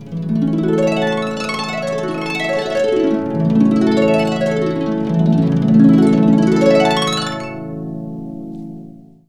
HARP BNX ARP.wav